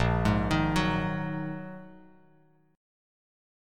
A#7sus4#5 chord